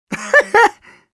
Voice file from Team Fortress 2 Russian version.
Scout_laughshort02_ru.wav